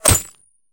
bullet_impact_glass_02.wav